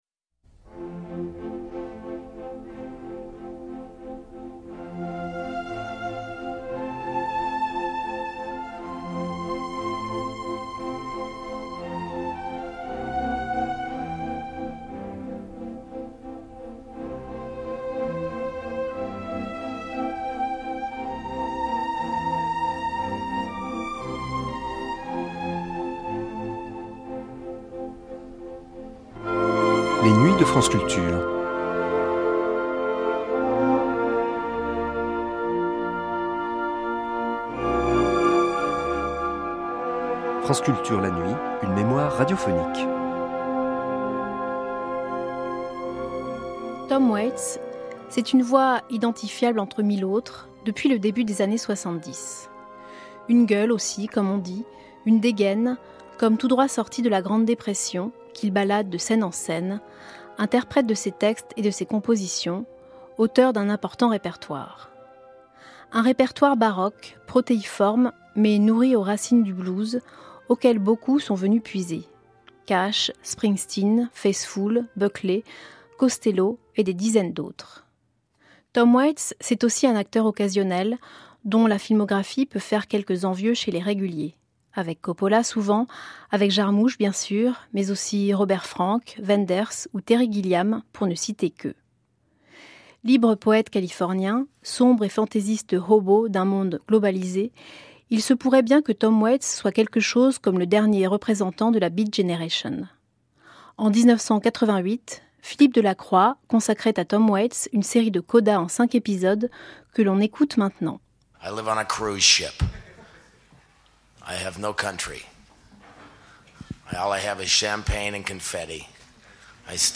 Une gueule, une dégaine, comme tout droit sorti de la grande dépression, qu’il balade de scène en scène, interprétant ses propres textes. 5 entretiens exceptionnels avec ce libre poète.